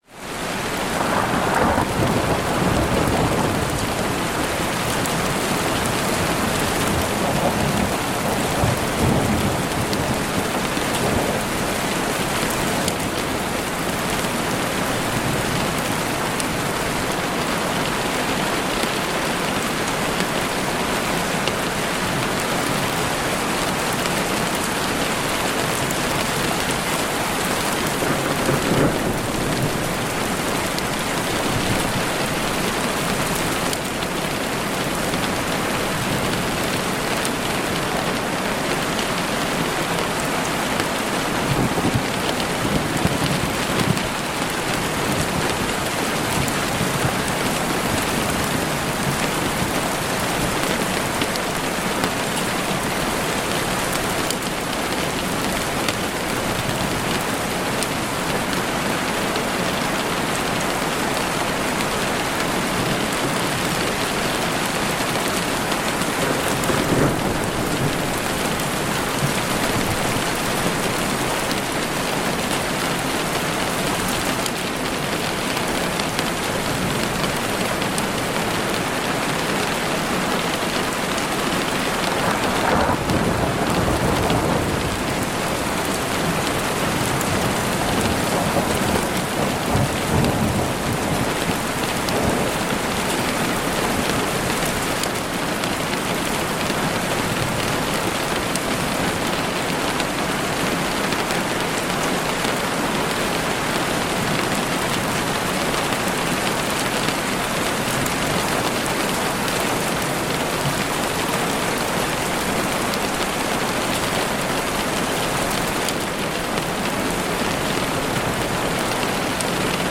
(Ads may play before the episode begins.)The sky opens with a low growl of thunder.